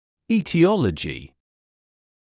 [ˌiːtɪ’ɔləʤɪ] [ˌи:ти’олэджи] этиология (US etiology)